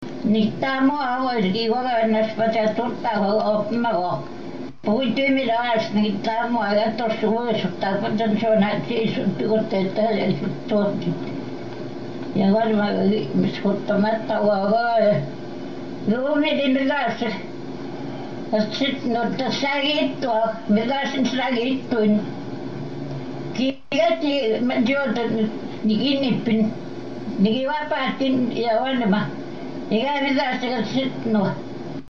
It sounds like a a Sino-Tibetan language, or some other tonal language from Southeast Asia, maybe.